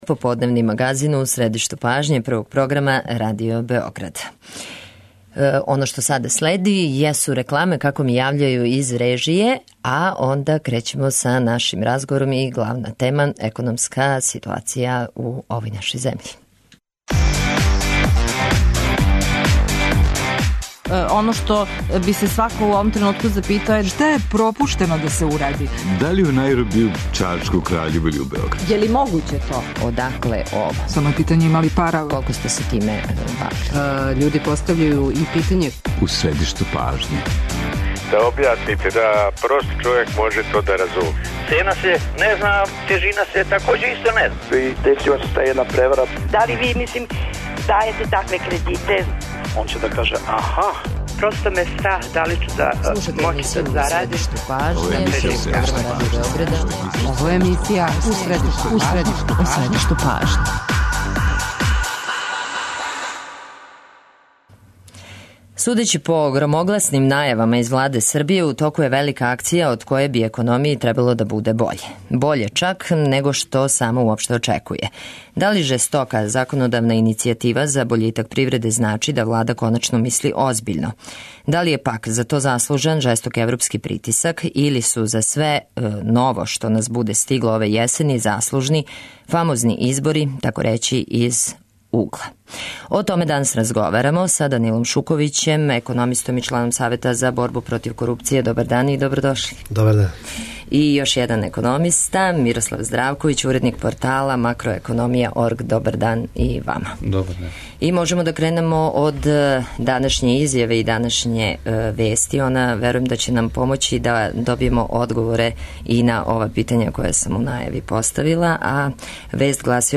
доноси интервју са нашим најбољим аналитичарима и коментаторима